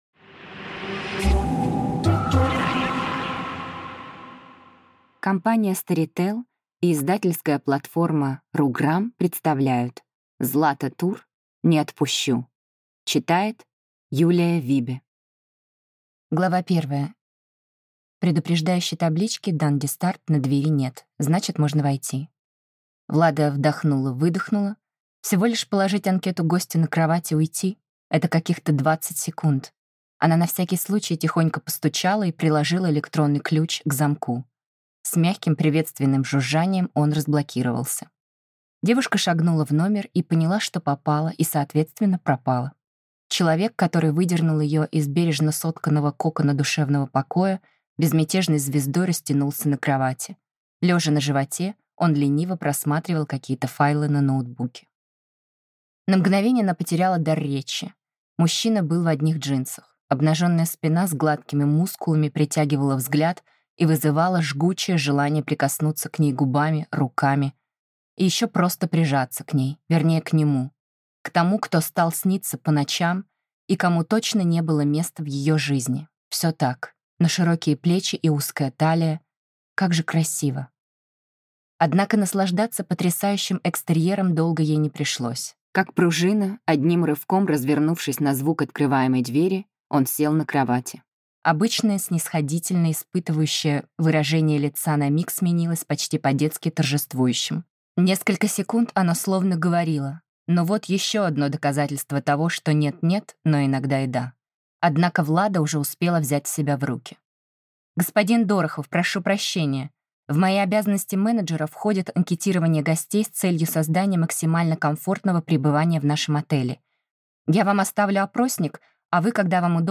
Аудиокнига Не отпущу | Библиотека аудиокниг
Прослушать и бесплатно скачать фрагмент аудиокниги